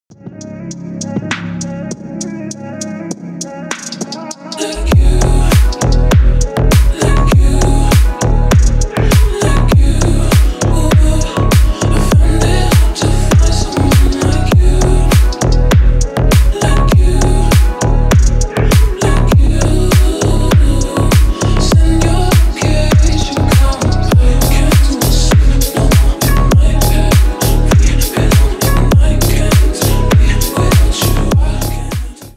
bass boosted
slowed
ремиксы